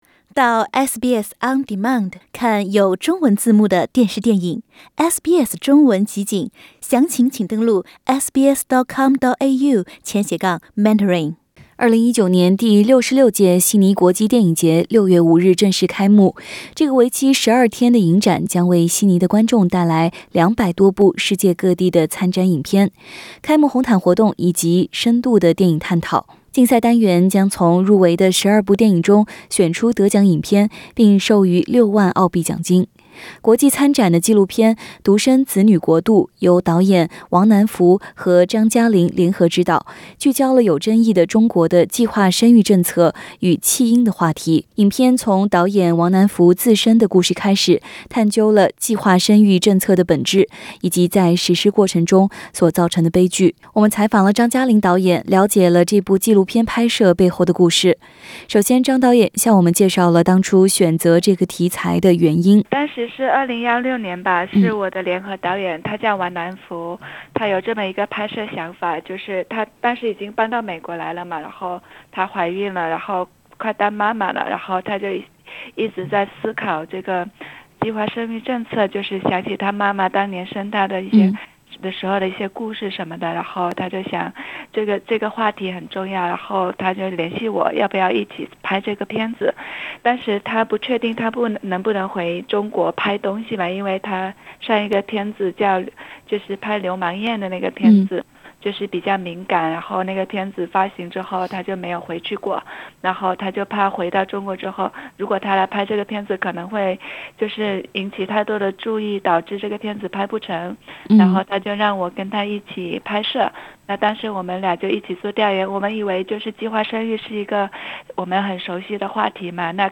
首先她向我们介绍了当初选择这个题材的原因。